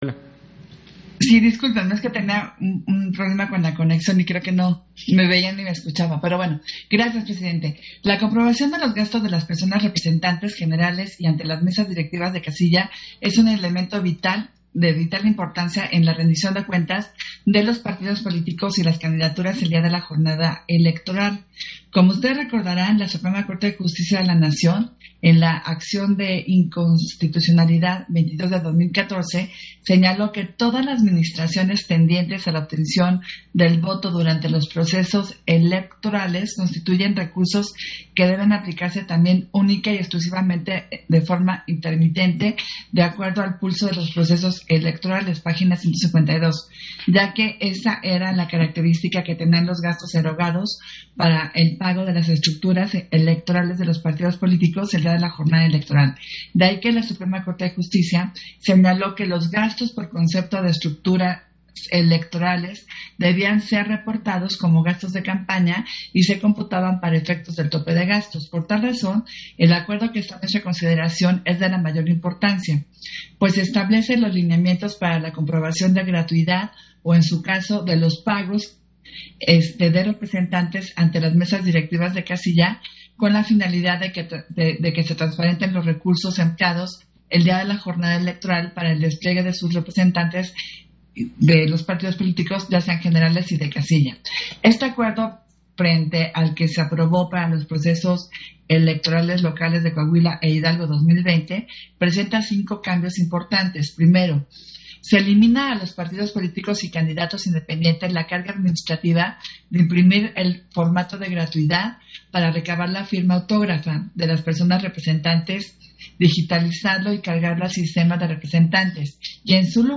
040521_AUDIO_INTERVENCIÓN-CONSEJERA-ADRIANA-FAVELA-PUNTO-4-SESIÓN-EXT.
Intervención de Adriana Favela en el punto 4 de la Sesión Extraordinaria, por el que se aprueban los lineamientos para la comprobación de gastos de representantes generales ante las mesas directivas de casilla